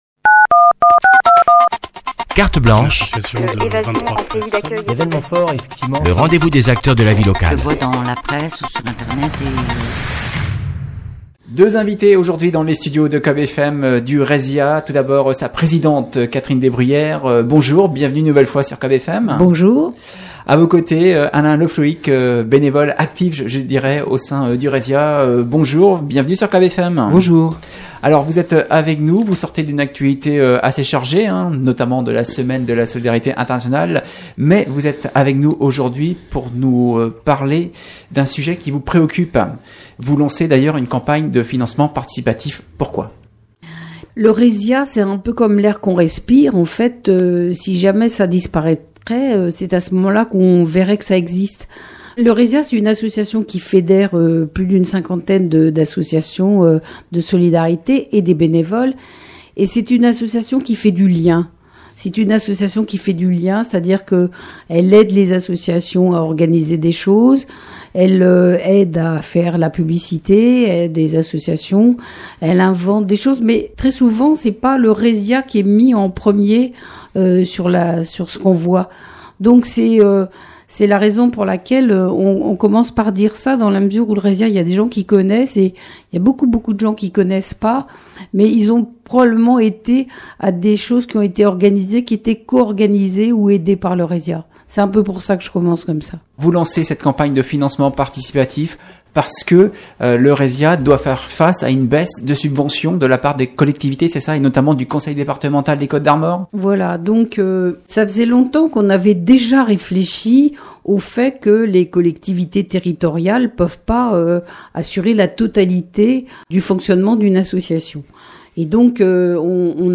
lancent un appel aux dons sur nos ondes